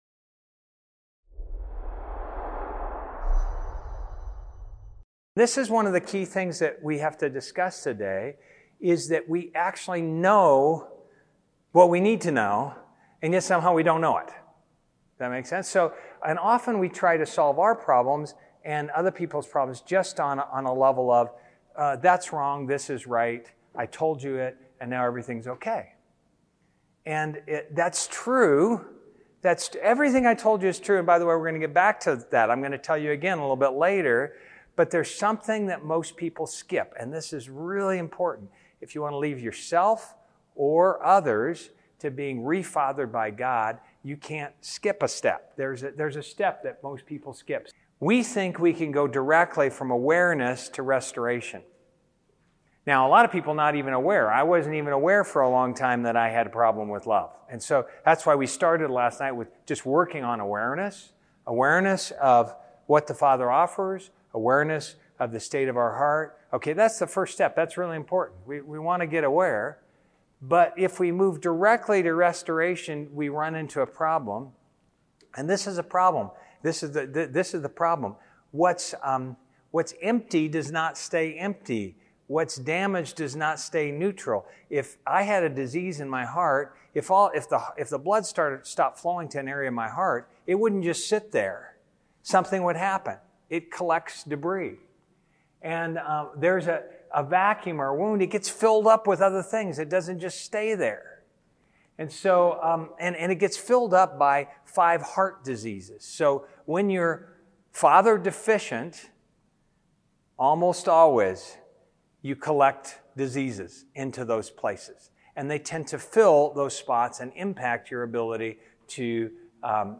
Event: ELF Post-Forum Seminar